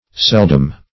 Seldom \Sel"dom\, a.